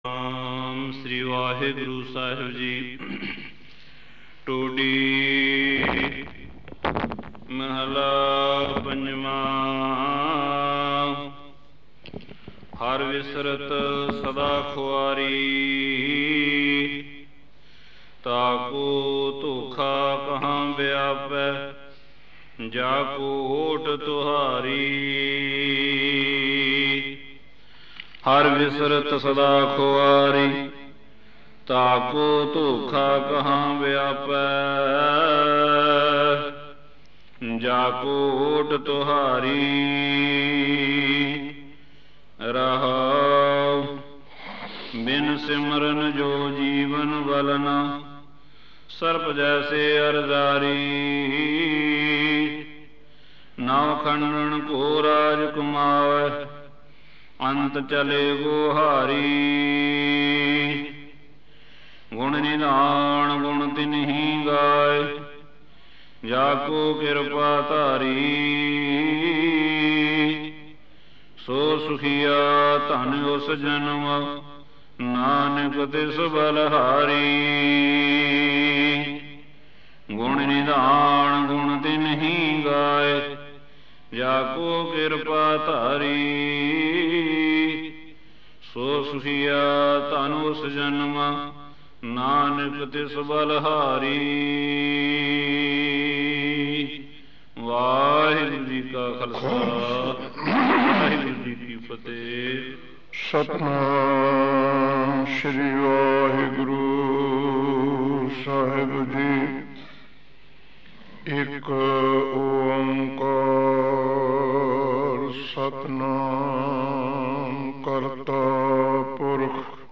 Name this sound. Genre: Gurmat Vichar